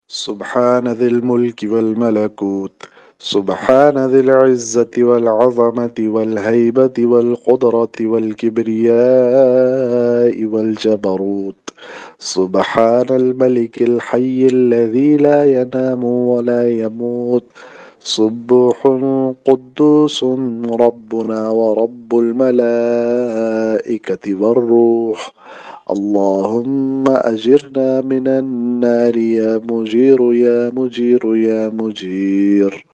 An Islamic audio bayan by Others on Ramadan - Dua.